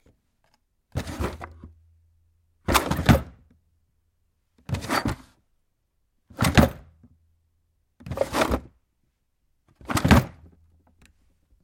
随机的" 抽屉里的木头垃圾打开关闭的响声
描述：垃圾抽屉木头打开关闭rattle.wav